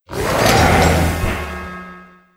P3D / Content / Sounds / Cries / 889_crowned.wav